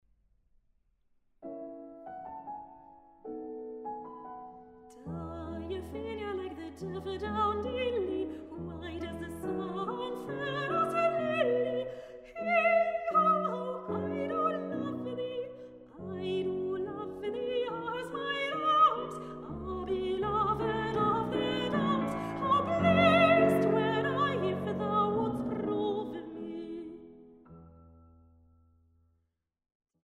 Englische Liebeslieder aus drei Jahrhunderten
Sopran
Laute